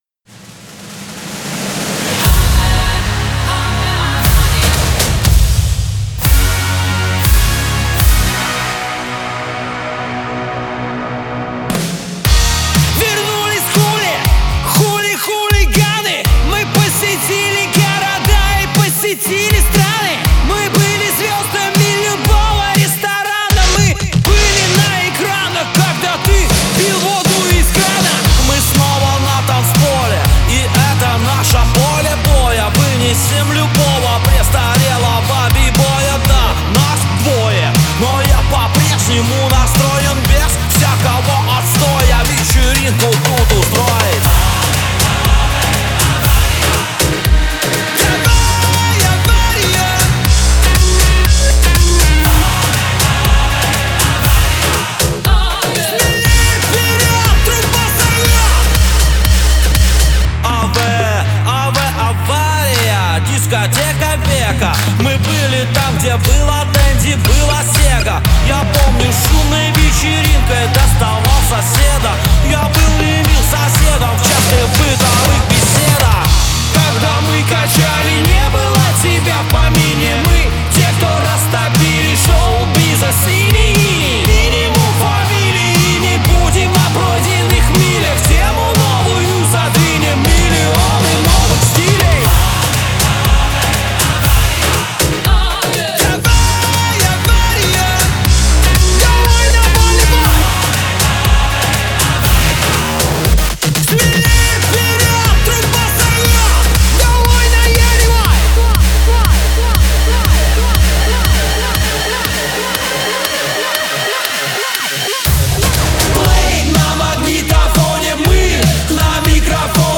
• Категории: Музыка 2020, Поп